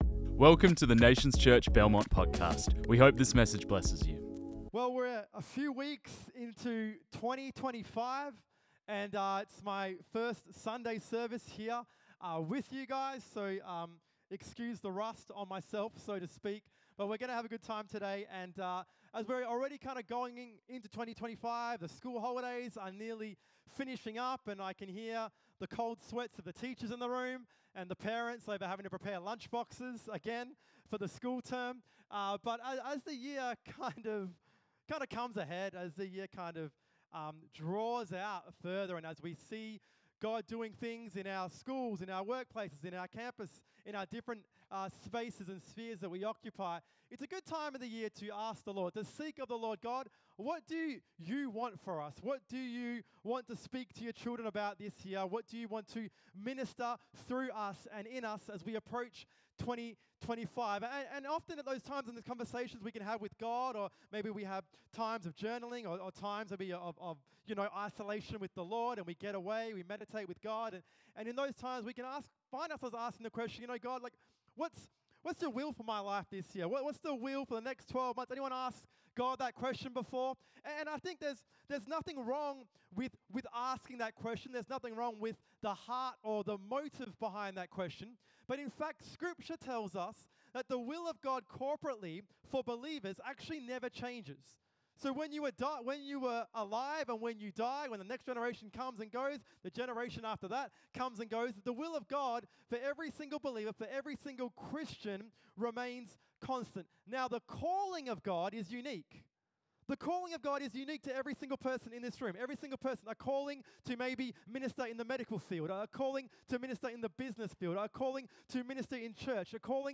This message was preached on 19 January 2024.
Nations Church Belmont